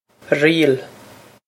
riail ree-il
This is an approximate phonetic pronunciation of the phrase.